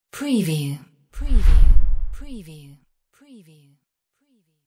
Transition whoosh 16
Stereo sound effect - Wav.16 bit/44.1 KHz and Mp3 128 Kbps
previewTLFE_DISTORTED_TR_WBHD16.mp3